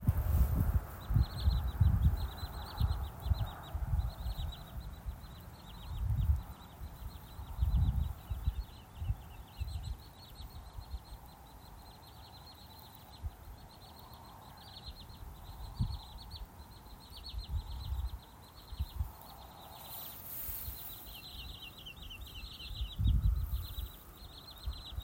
Birds -> Waders ->
Wood Sandpiper, Tringa glareola